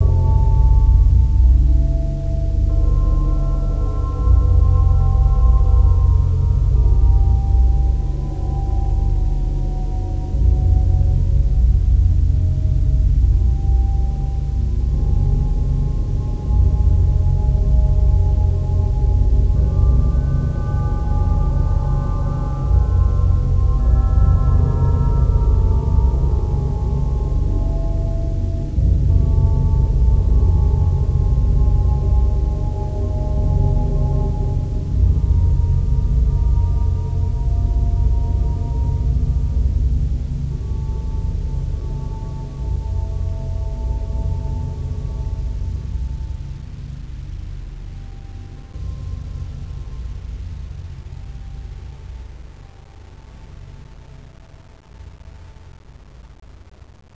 muhahaha.wav